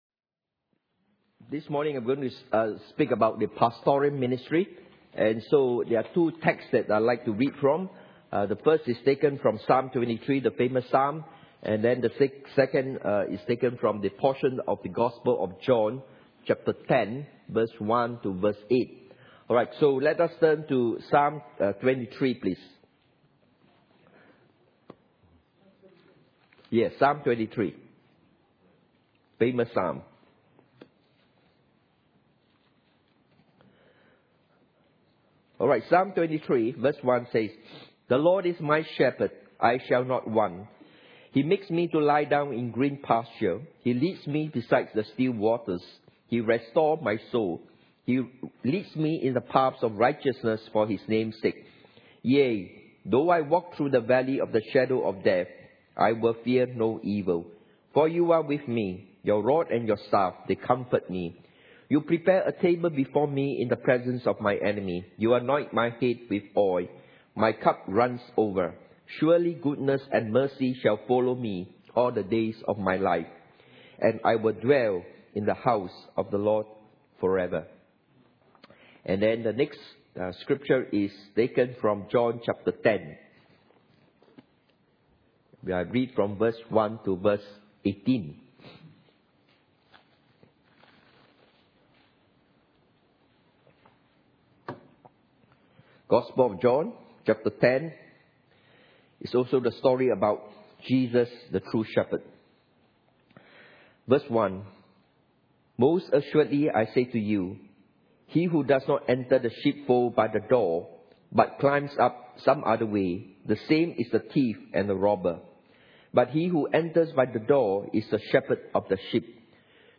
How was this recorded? The 5 Fold Gifts Service Type: Sunday Morning « The 5 Fold Gifts